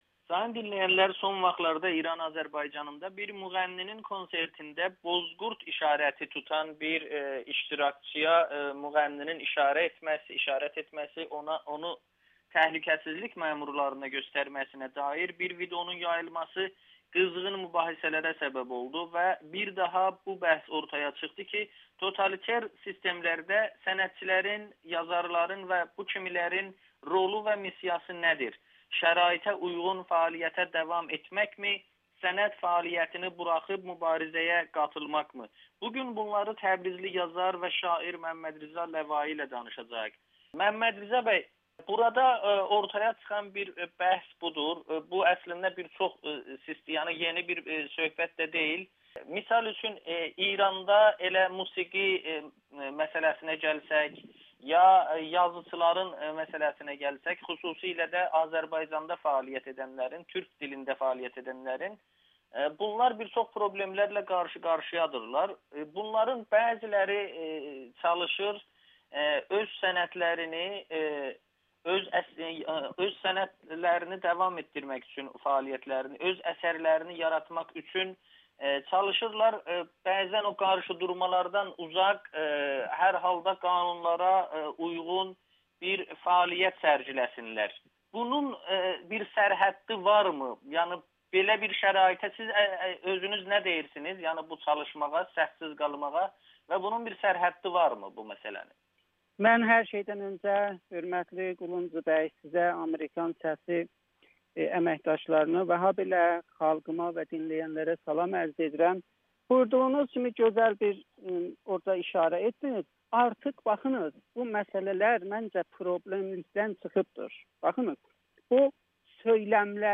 Təbrizli şair Amerikanın Səsinə müsahibə verib